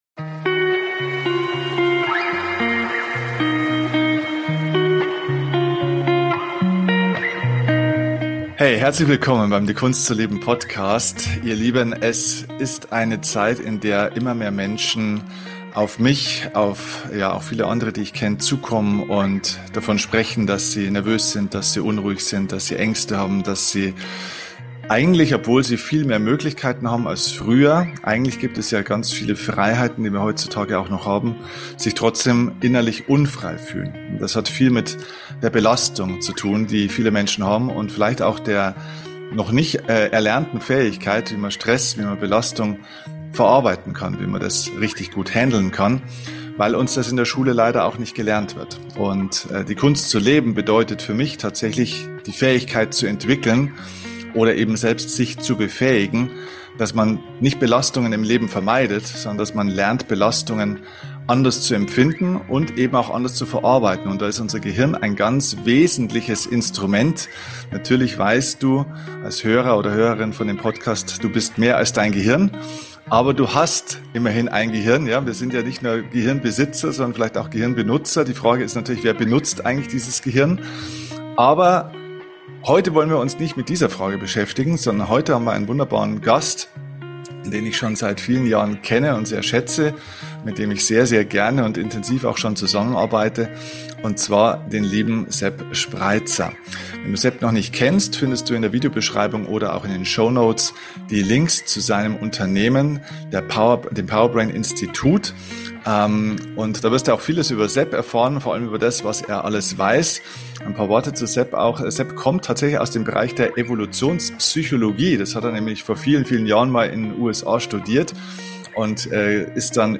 In unserem gemeinsamen Gespräch haben wir über mentales Gesundheitstraining gesprochen. Unsere mentale Stärke und Gesundheit sind wie ein Muskel, den wir trainieren können.